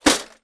TOOL_Toolbox_Close_mono.wav